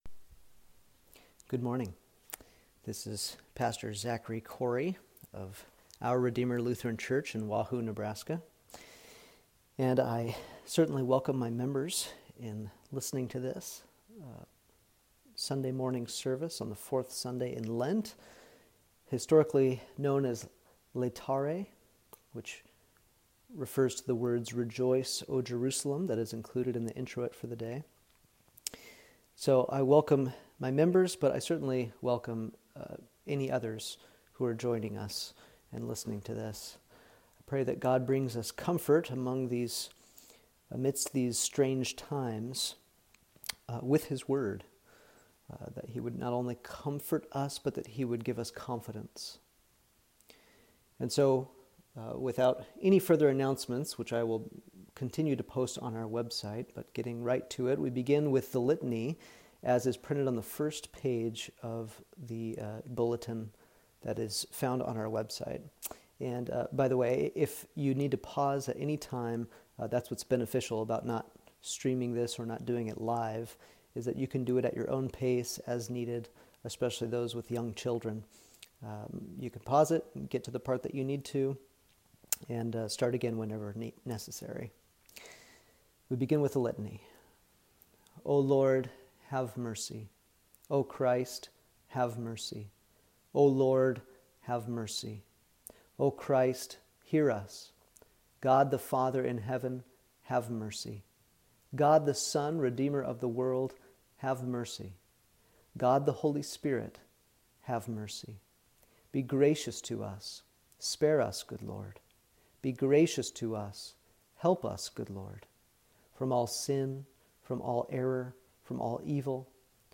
Recorded Service: Fourth Sunday in Lent – Laetare